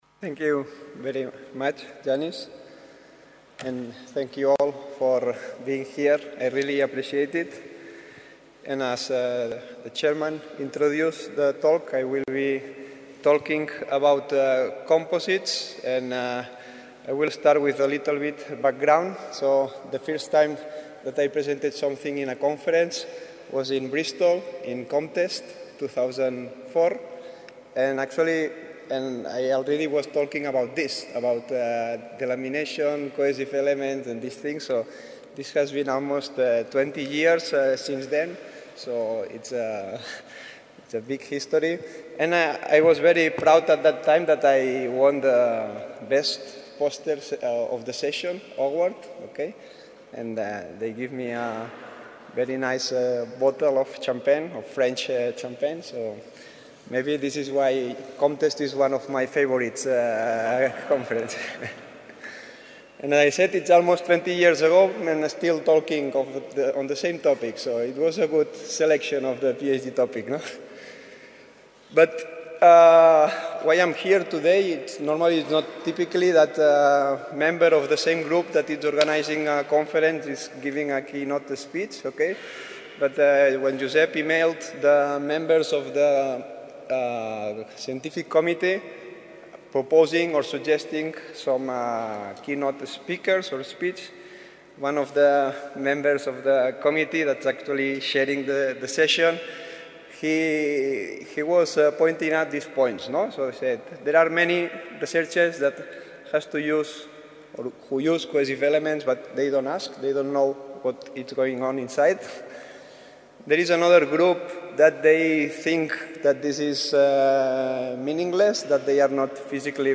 Ponència a l’11th International Conference on Composite Testing and Model Identification, Girona, Spain May 31 – June 2